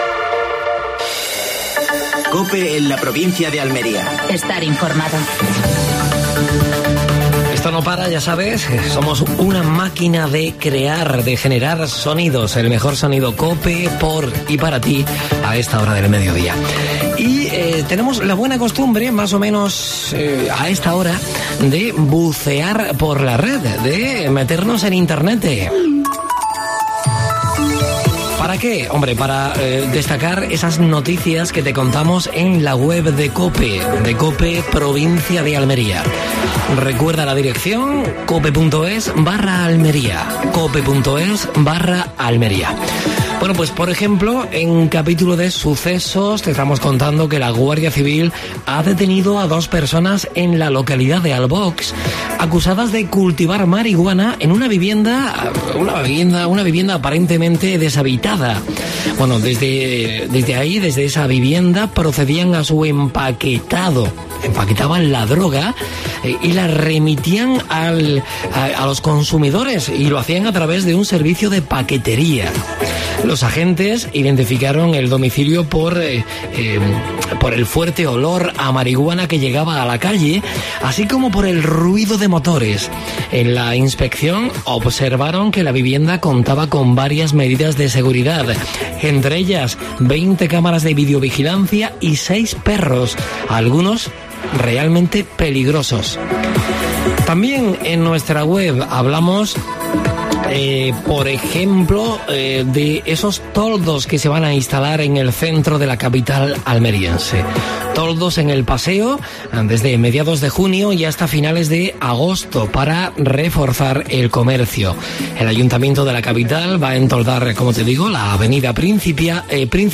AUDIO: Entrevista al alcalde de El Ejido, Francisco Góngora.